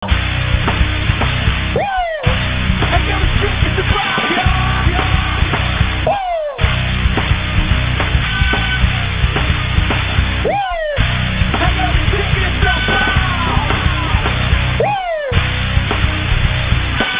Zvonenia na mobil*(Ringtones):